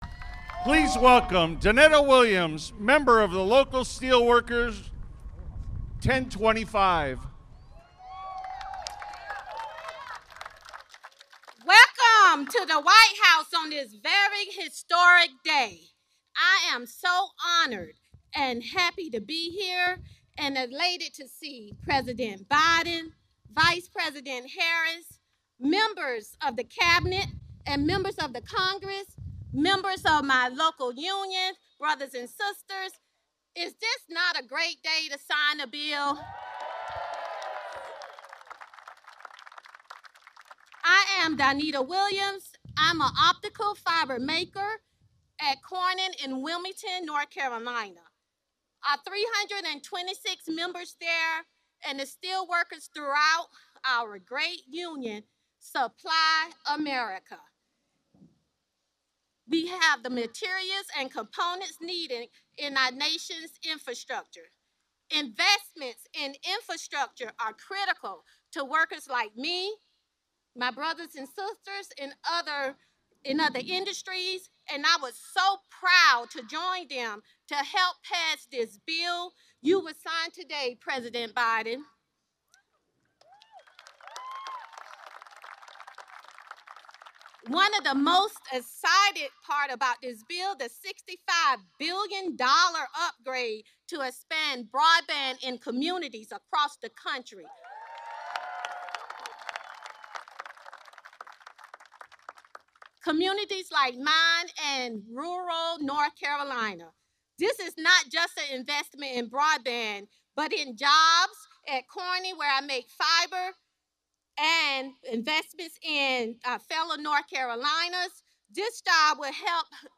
President_Biden_Signs_into_Law_his_Bipartisan_Infrastructure_Deal.mp3